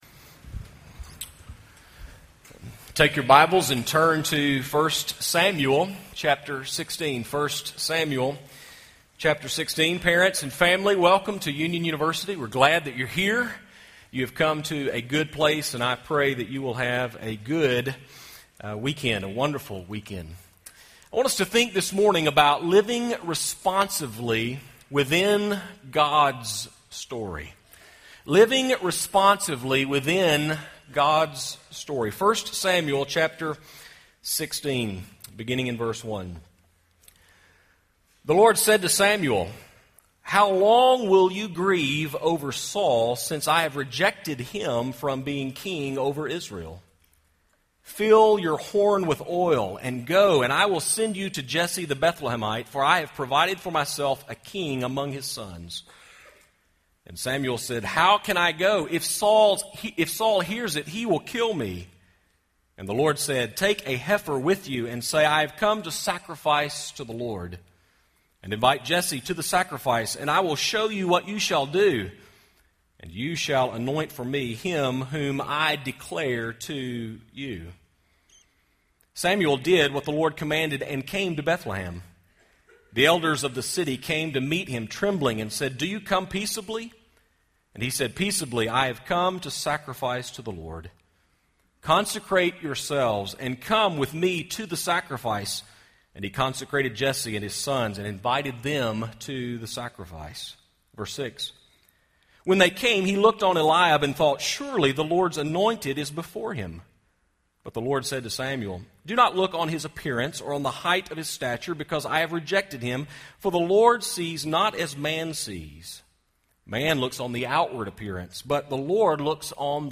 Family Weekend Chapel